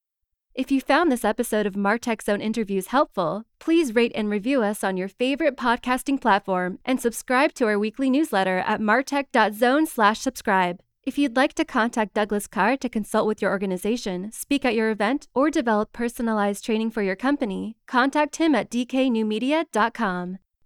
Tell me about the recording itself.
Within an hour I had a perfectly executed voice-over that I’m now using in my next episode.